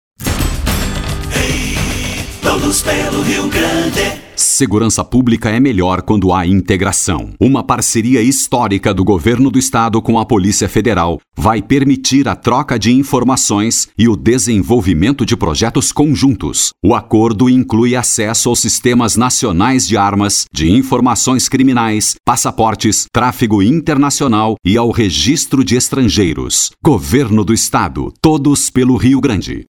Locutor voz Grave, a disposição para gravação de Spots de Rádio e TV, bem como Esperas Telefônicas e documentários
• spot